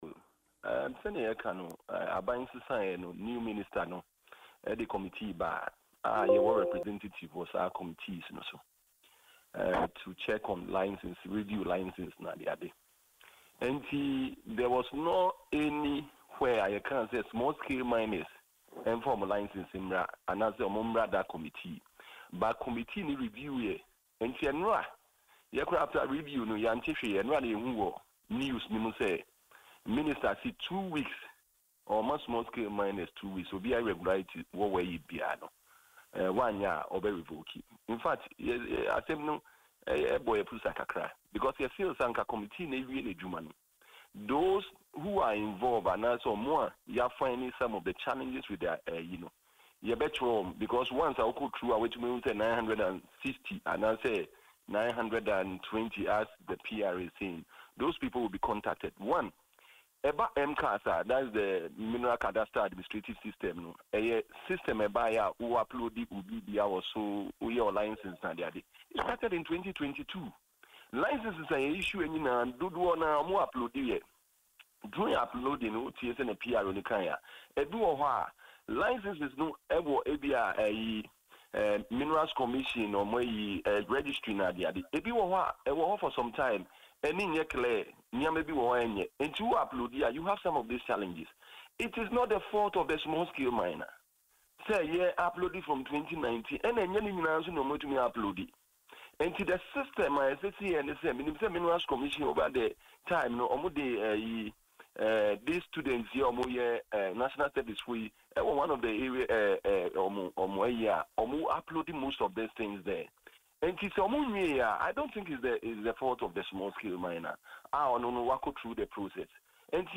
Speaking in an interview on Adom FM’s morning show Dwaso Nsem